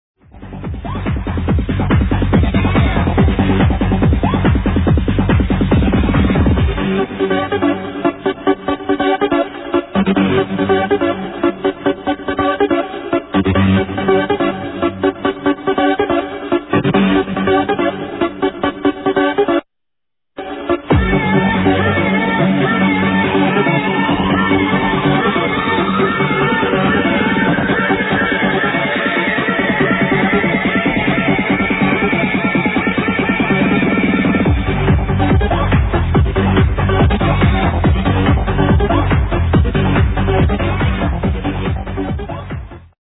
sounds like a famous tune remixed..